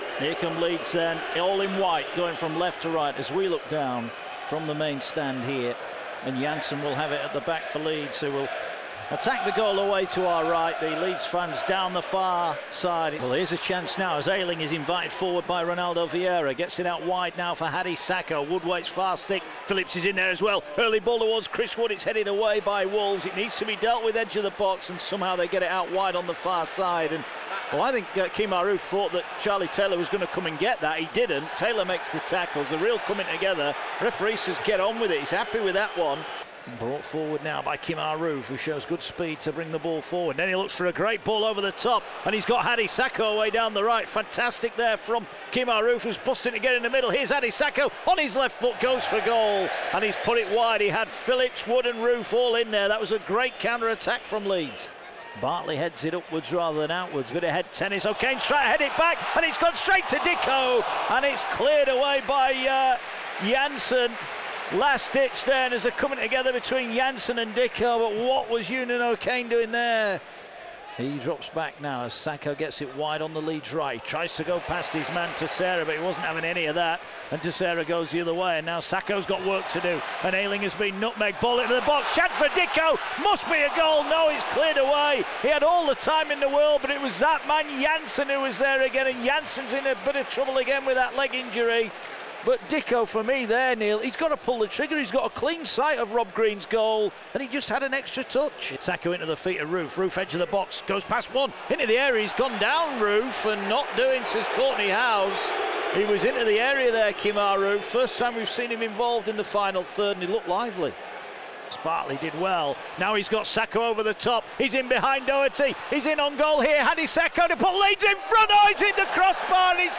Wolves 0-1 Leeds full highlights